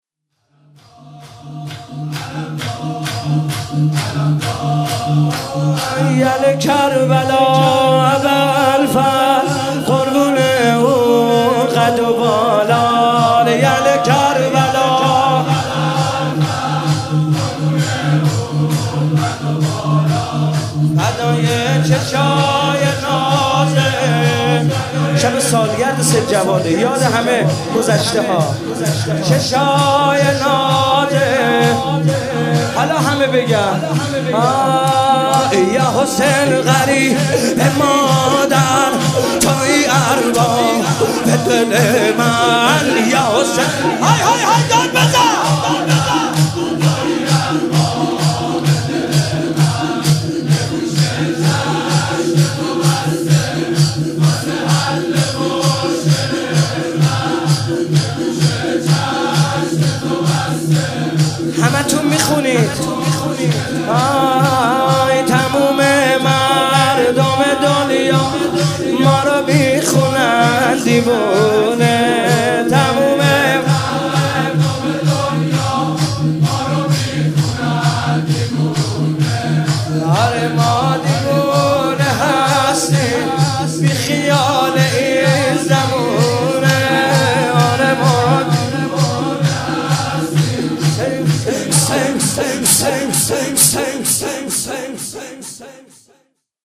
روضه محمود کریمی